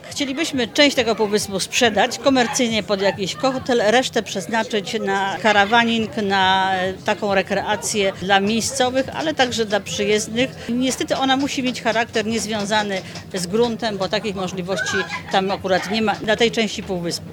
– Chodzi o to, by półwysep stał się bazą turystyczną – mówi burmistrz Bogusława Towalewska.